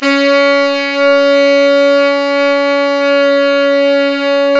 SAX_sfc#4x   230.wav